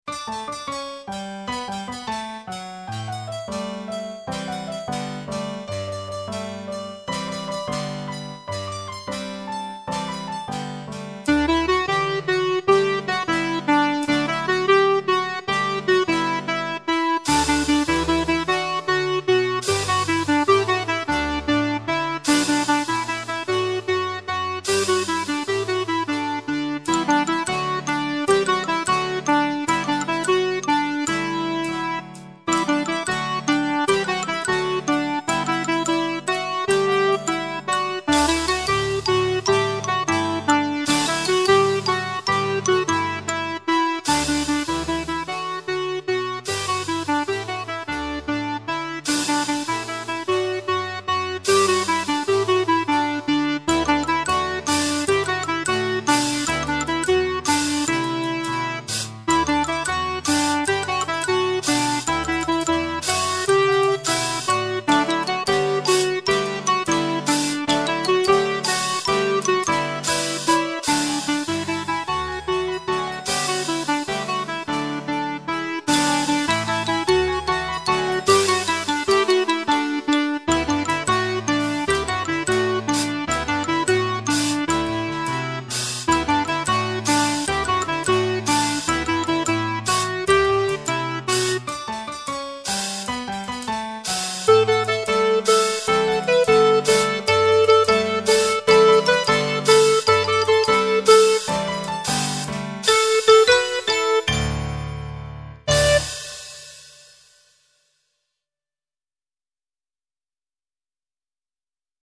Composizioni per coro di voci bianche: